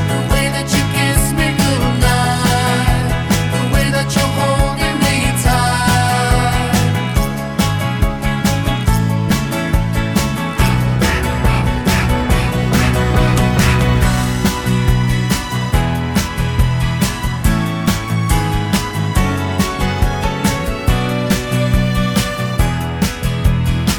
One Semitone Down Pop (1970s) 3:00 Buy £1.50